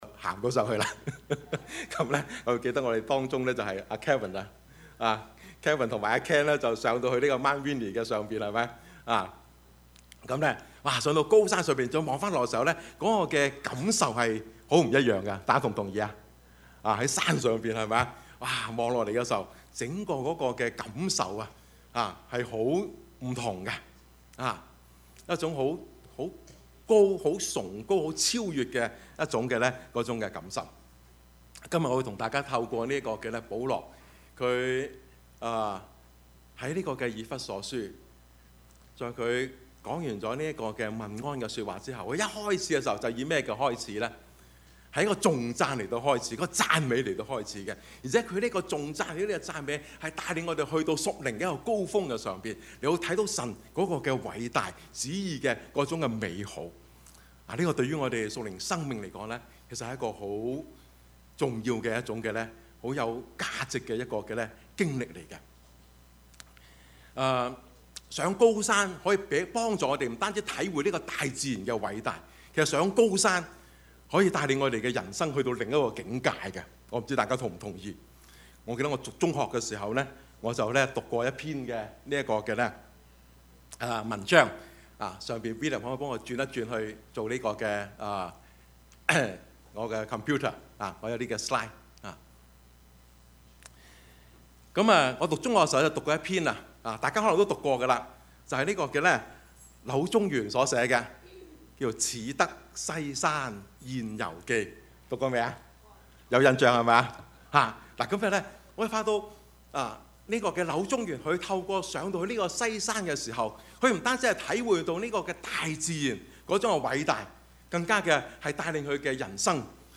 Passage: 以弗所書 1:3-14 Service Type: 主日崇拜
Topics: 主日證道 « 我是誰？